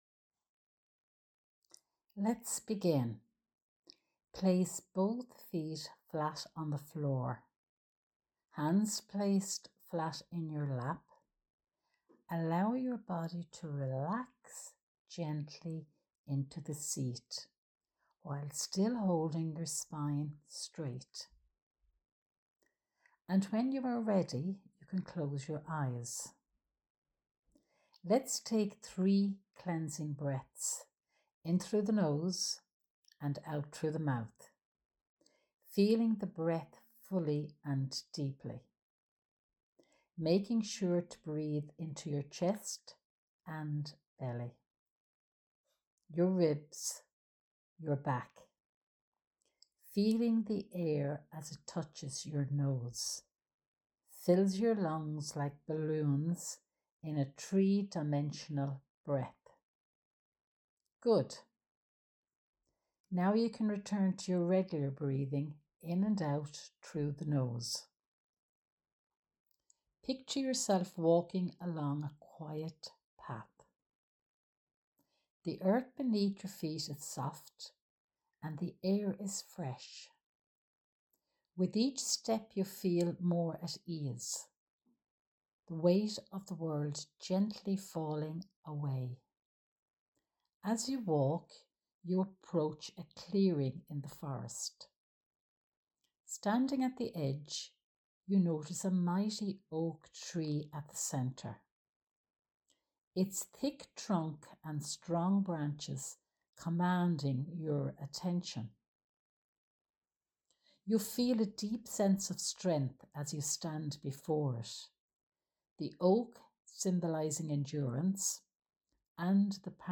Ogham Meditation
CC05-meditation.mp3